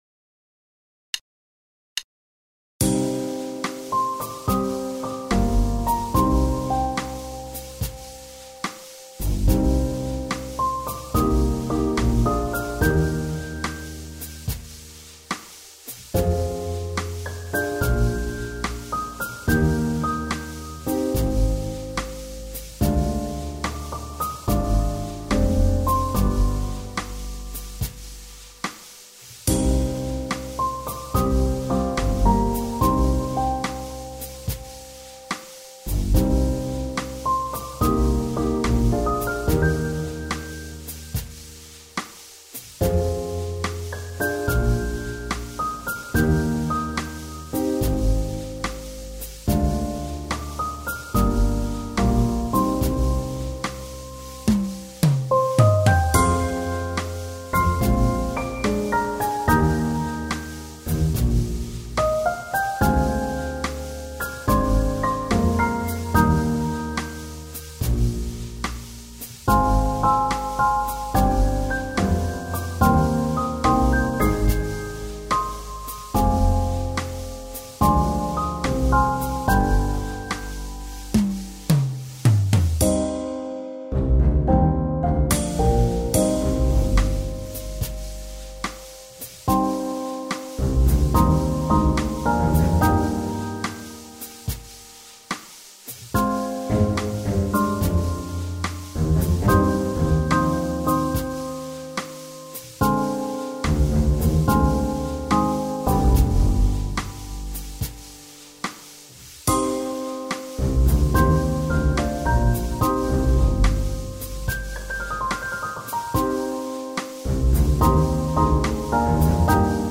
スローテンポロング穏やか